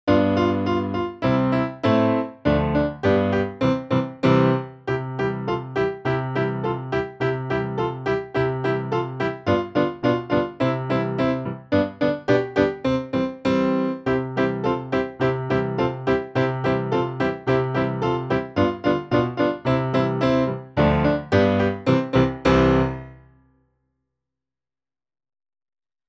Prsi-prsi-C-dur.mp3